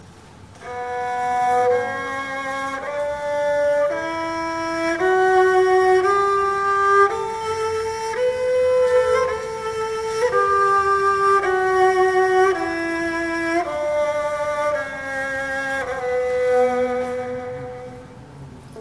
Azerbaijan Recorded using TASCAM DR100mkII.
标签： turkish tar makam geoip compmusic